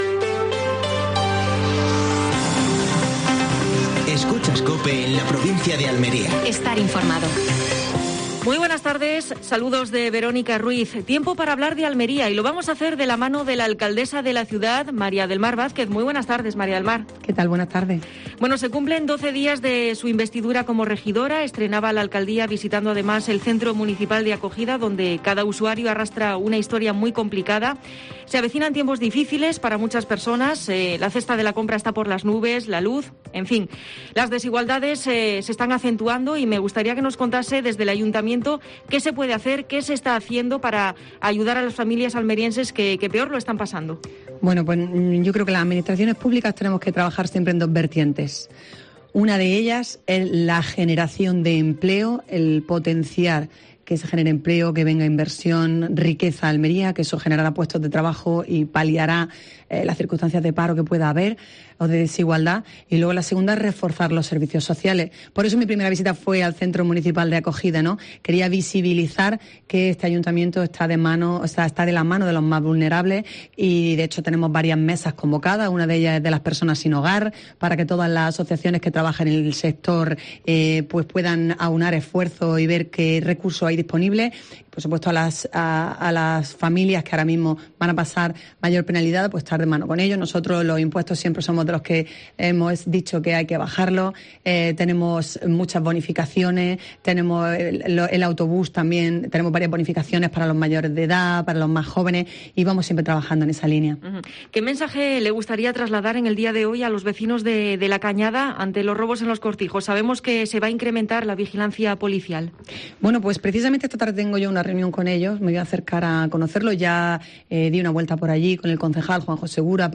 Última hora en Almería. Entrevista a María del Mar Vázquez (alcaldesa de Almería).